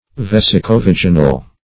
Meaning of vesicovaginal. vesicovaginal synonyms, pronunciation, spelling and more from Free Dictionary.
Search Result for " vesicovaginal" : The Collaborative International Dictionary of English v.0.48: Vesicovaginal \Ves`i*co*vag"i*nal\, a. (Anat.) Of or pertaining to the bladder and the vagina.